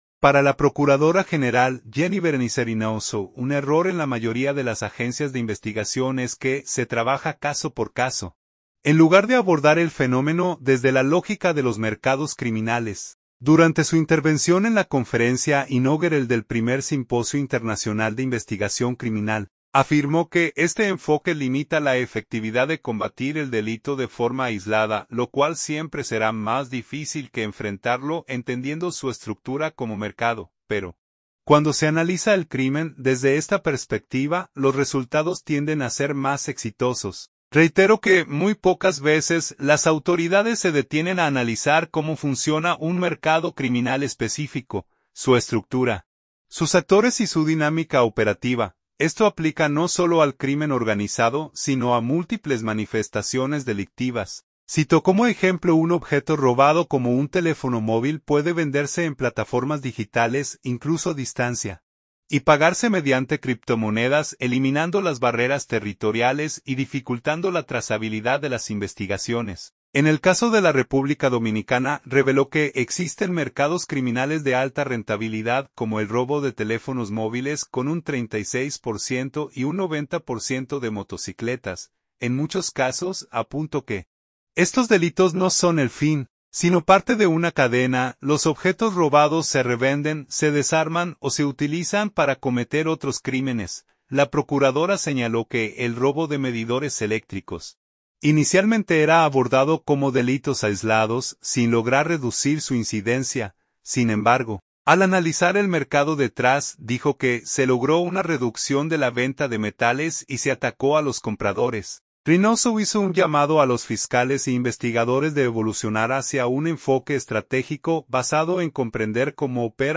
Durante su intervención en la conferencia inaugural del Primer Simposio Internacional de Investigación Criminal, afirmó que este enfoque limita la efectividad de combatir el delito de forma aislada lo cual siempre será más difícil que enfrentarlo entendiendo su estructura como mercado, pero, cuando se analiza el crimen desde esta perspectiva, los resultados tienden a ser más exitosos.